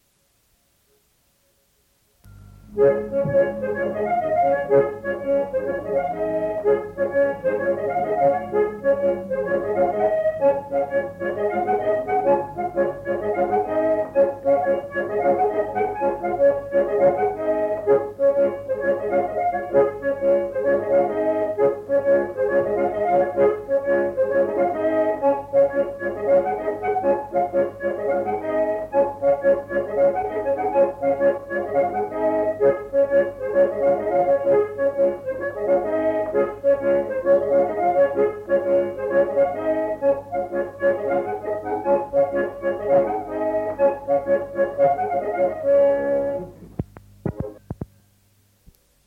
Genre : morceau instrumental
Instrument de musique : accordéon diatonique
Danse : rondeau
Ecouter-voir : archives sonores en ligne